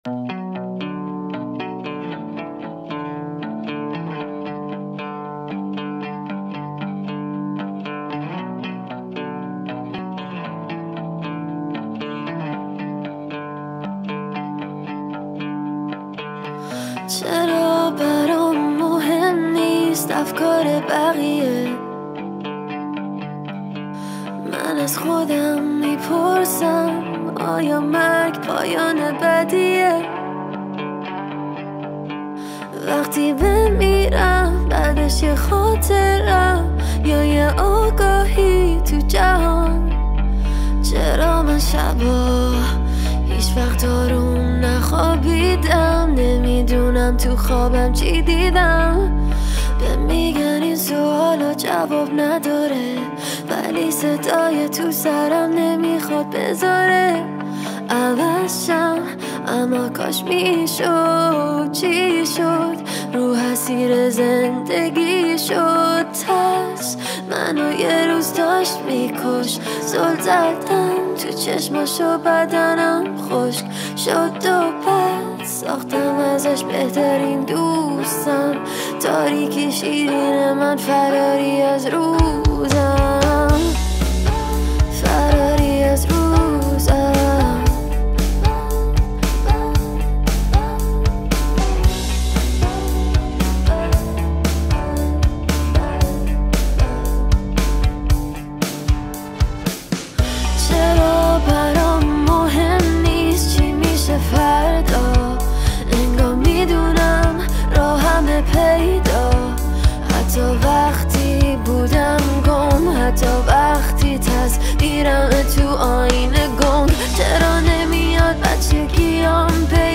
رپ
آهنگ با صدای زن
اهنگ ایرانی